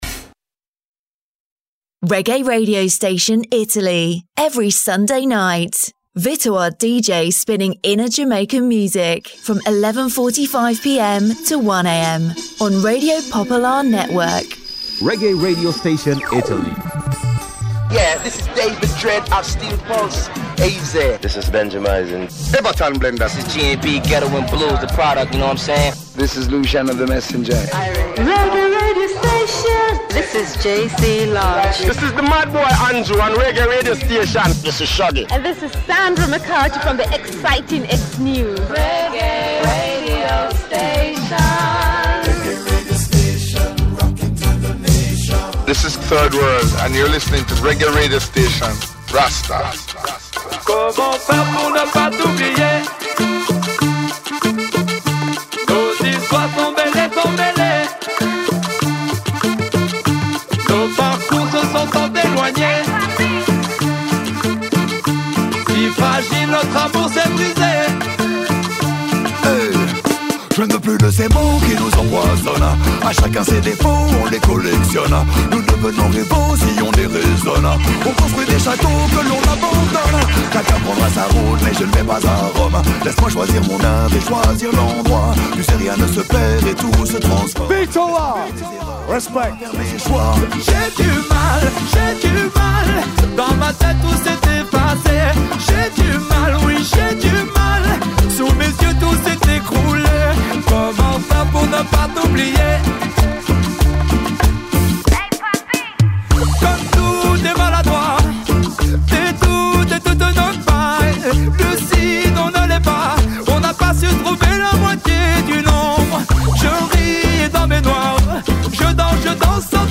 A ritmo di Reggae Reggae Radio Station accompagna discretamente l’ascoltatore in un viaggio attraverso le svariate sonorità della Reggae Music e...